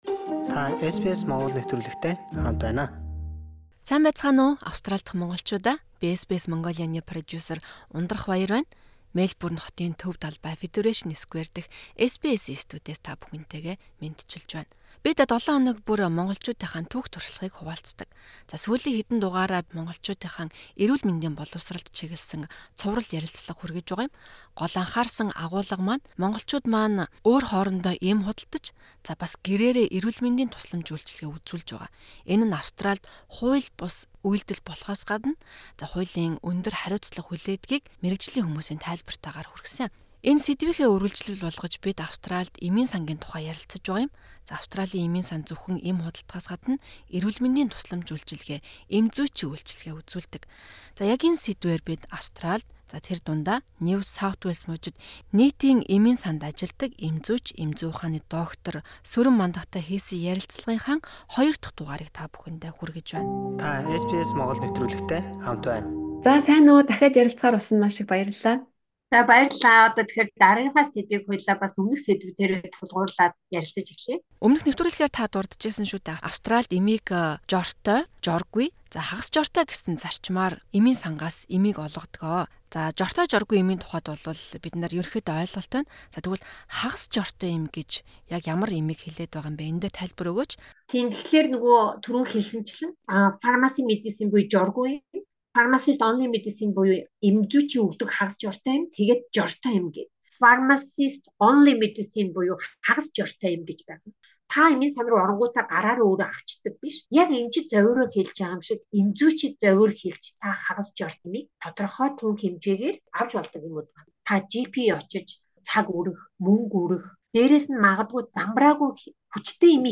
Ярилцлагын эхний хэсэг